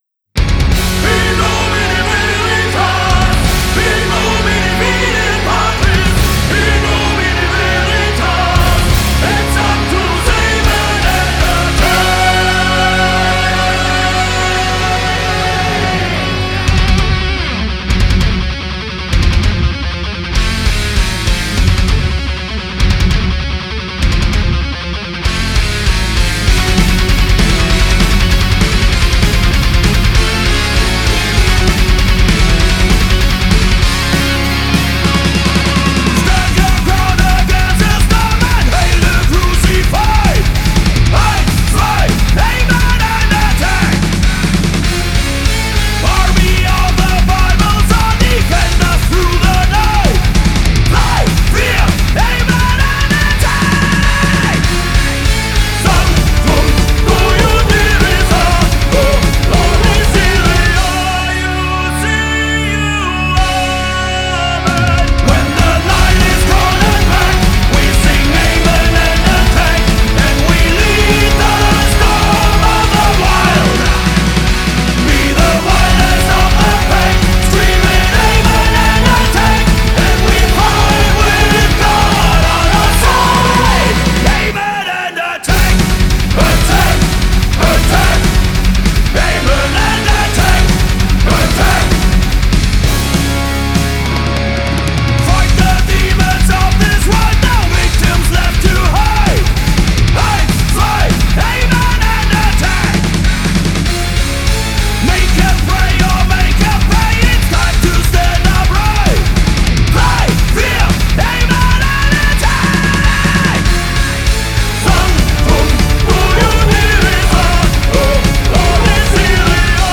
For me mostly hard rock, heavy metal and power metal.